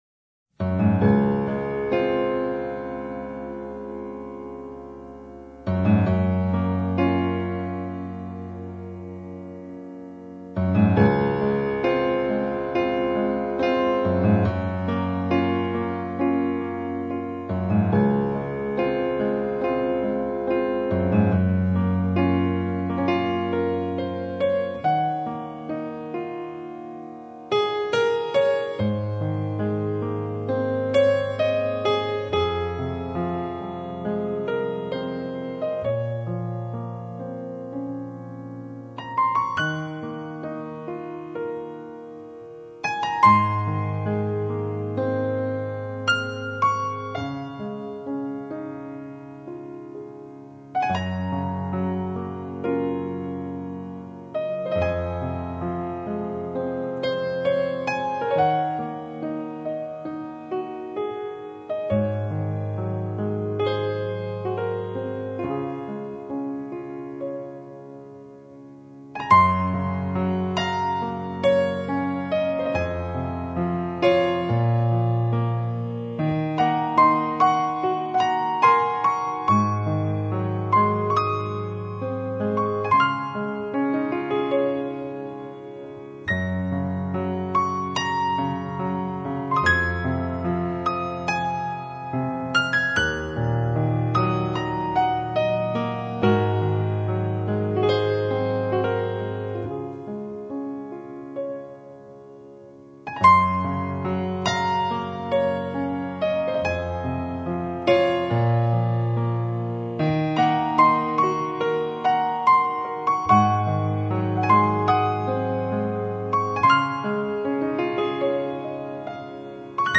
曲风缓慢恬静，带着丝丝淡淡的忧伤
那么重那么沉的音键按断了我愉快的周末，偌大的房间里只剩下我一个人呼与吸。